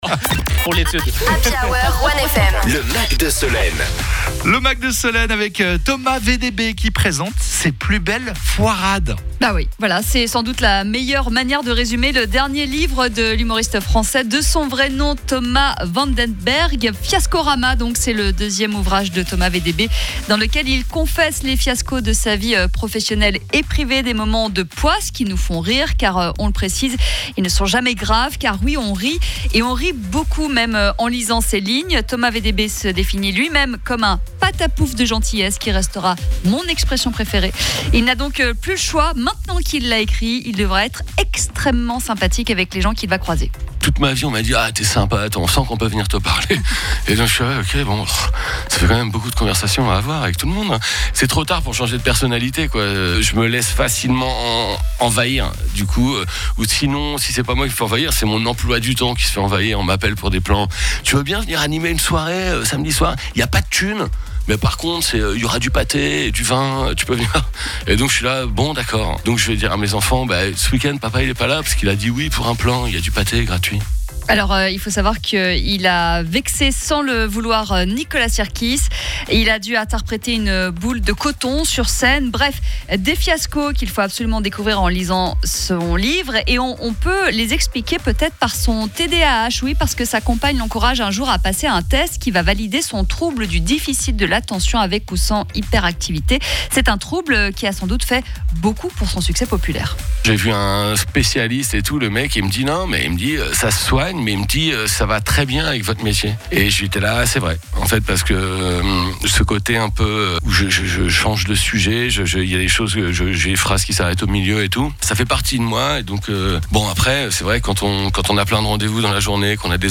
L’interview de Thomas VDB est à retrouver en intégralité ci-dessous:
Thomas VDBHumoriste